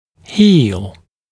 [hiːl][хи:л]заживать, заживляться; консолидироваться (о переломе)